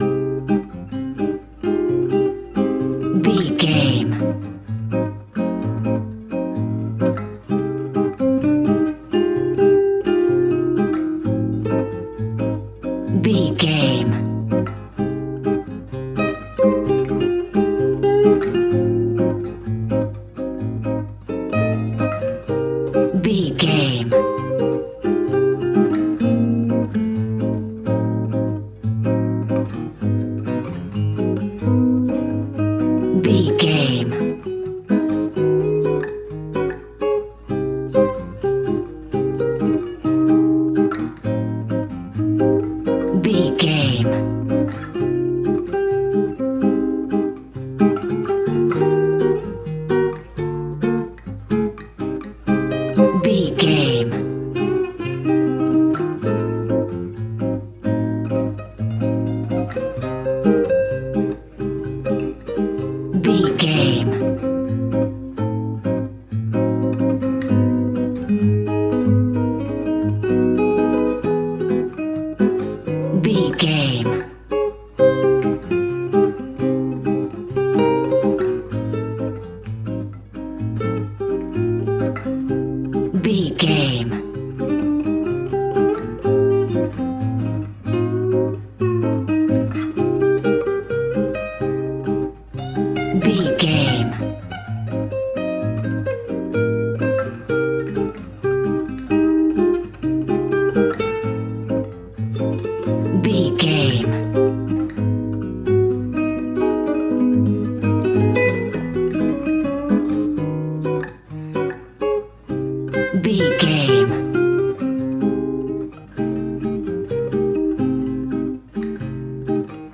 Aeolian/Minor
flamenco
maracas
percussion spanish guitar